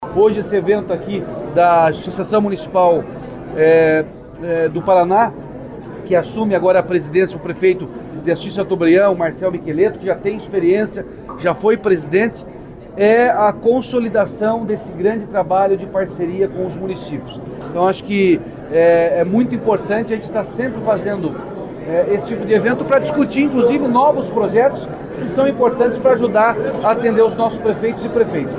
O governador do Paraná, Ratinho Junior (PSD), também esteve na cerimônia de posse, realizada no Campus Ecoville da Universidade Positivo, na capital. O chefe do Executivo defendeu a parceria entre estado e municípios.